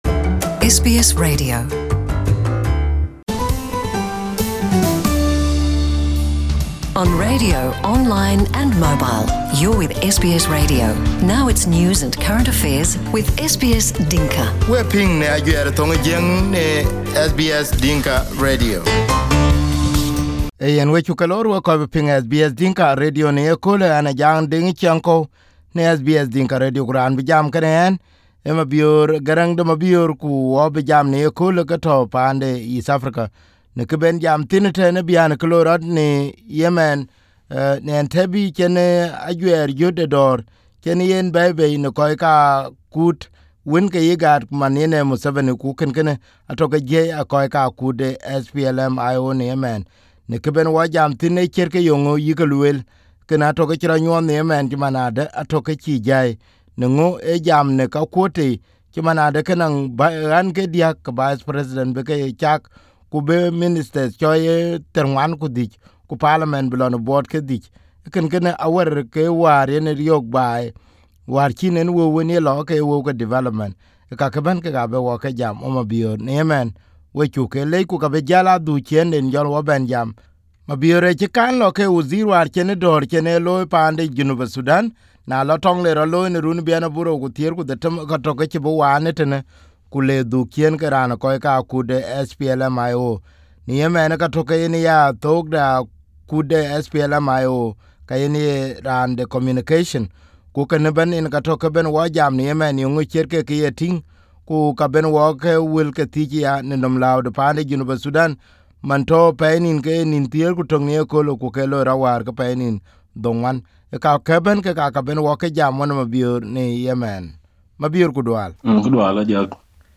Interview with Mabior Garang de Mabior who is one of the senior officials for the SPLM IO. The recent proposal in Uganda was rejected by the opposition. here is the interview on SBS Dinka radio this morning.